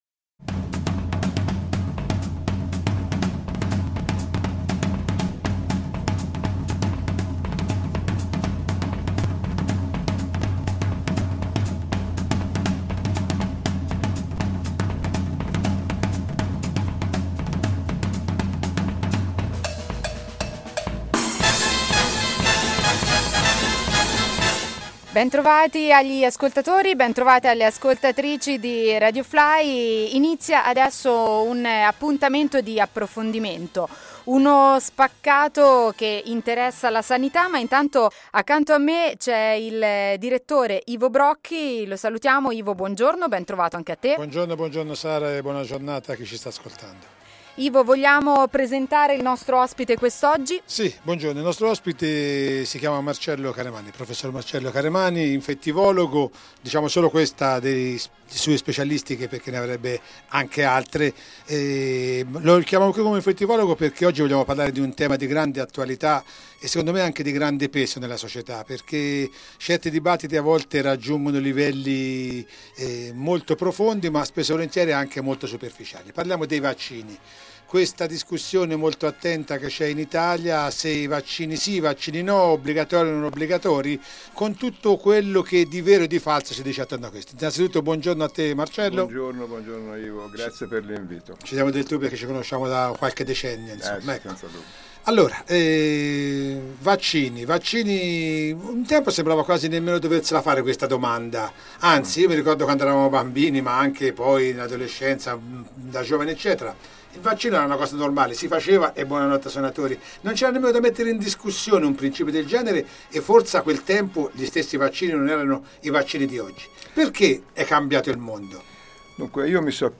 Trattiamo il tema dei vaccini in questo appuntamento delle interviste Fly